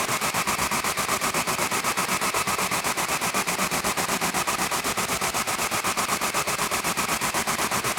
Index of /musicradar/stereo-toolkit-samples/Tempo Loops/120bpm
STK_MovingNoiseE-120_02.wav